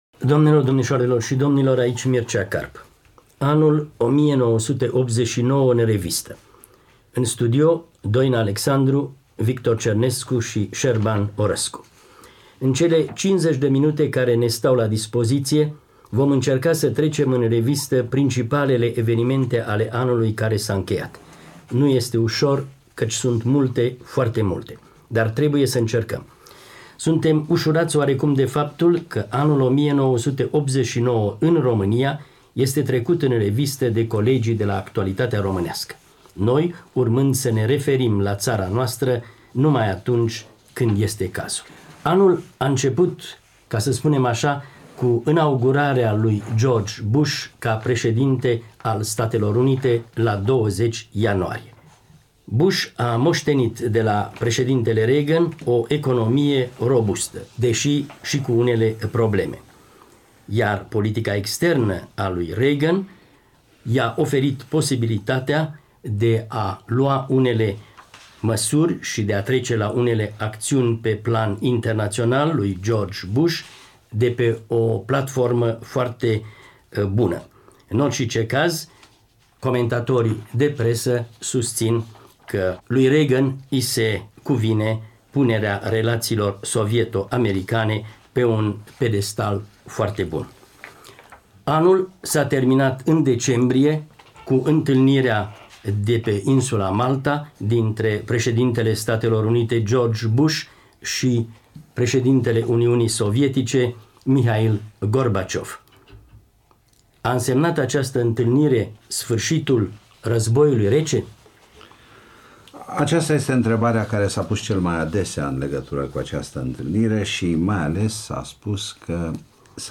„Actualitatea românească”, radio Europa Liberă. 31 decembrie 1989.